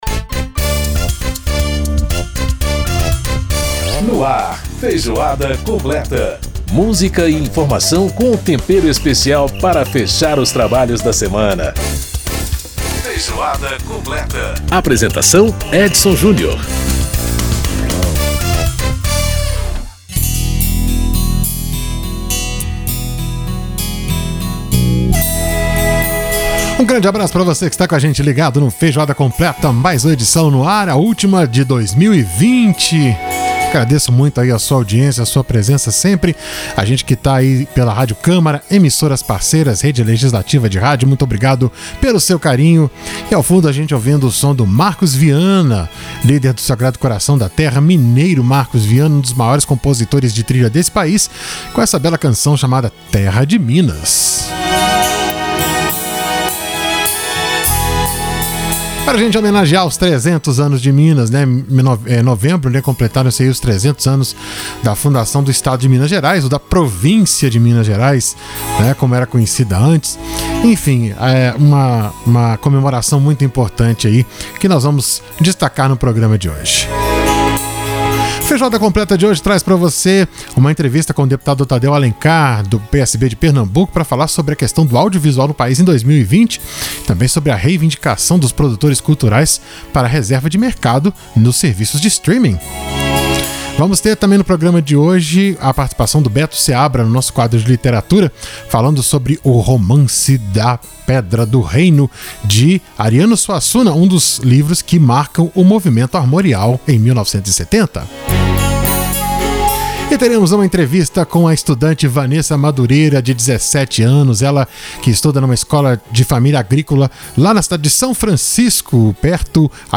Bloco 1 - Para saber os detalhes, demandas e como fica a arte e a cultura durante a pandemia, o programa Feijoada Completa desta semana conversa com o deputado Tadeu Alencar (PSB-PE), Coordenador da Frente Parlamentar Mista em Defesa do Cinema e Audiovisual.